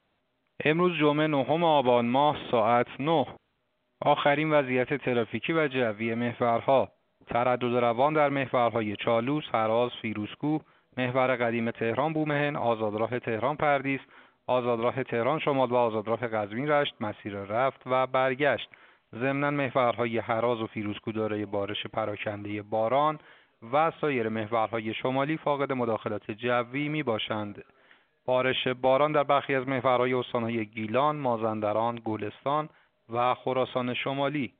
گزارش رادیو اینترنتی از آخرین وضعیت ترافیکی جاده‌ها ساعت ۹ نهم آبان؛